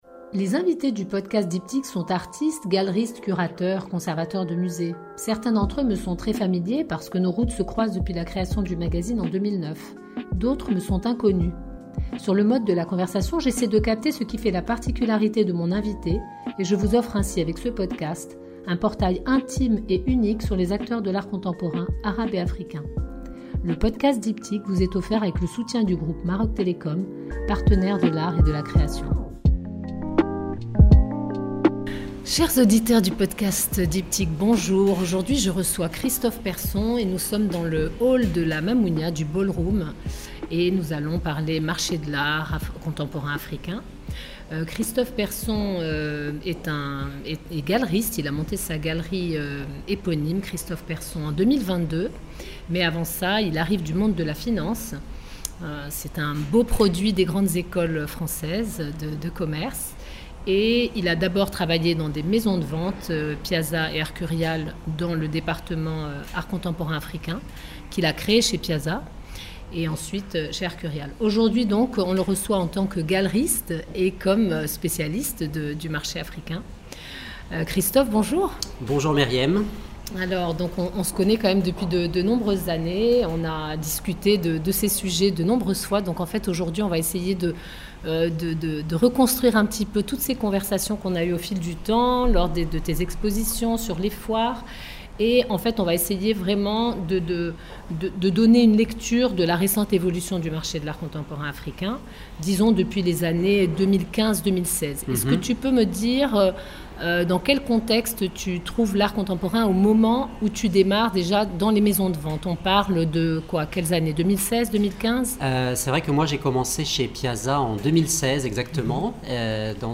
Cet épisode a été enregistré en février 2024 à La Mamounia à l'occasion de la foire d'art contemporain africain 1-54, avec Maroc Télécom , partenaire des arts et de la création.